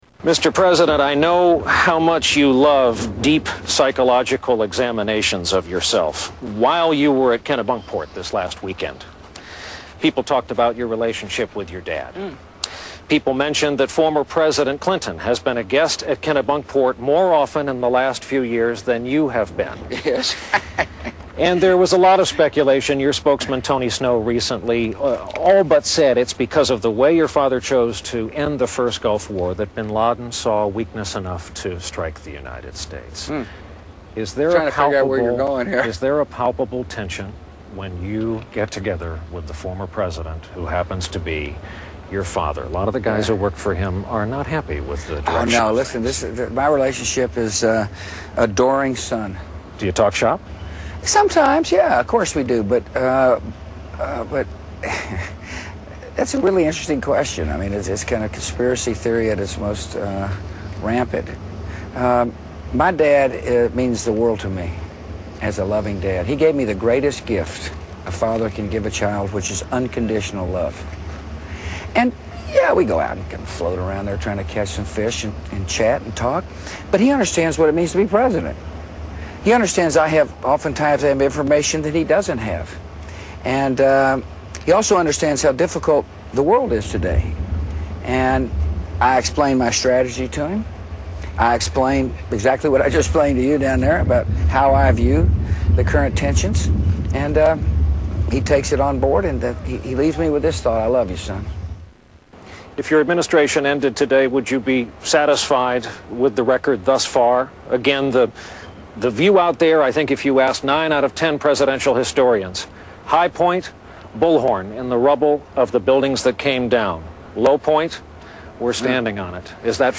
Interview with George Bush
Tags: Media News Brian Williams News Anchor NBC Nightly News